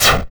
fireball.wav